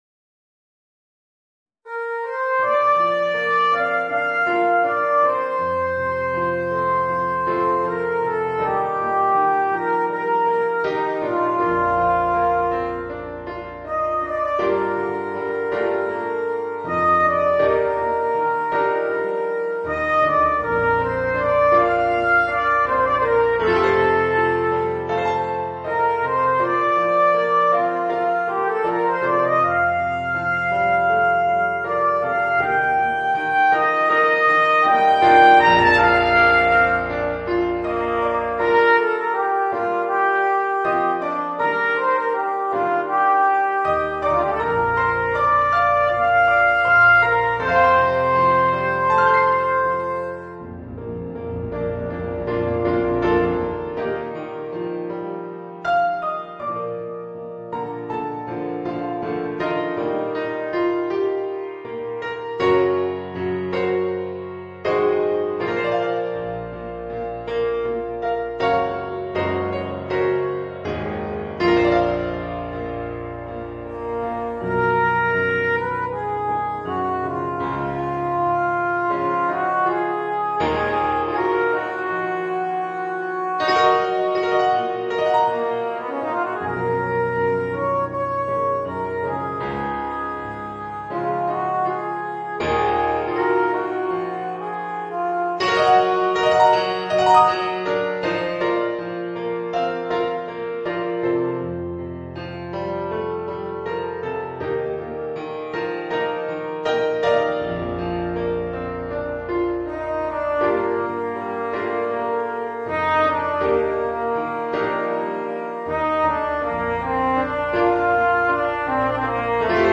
Voicing: Cornet and Piano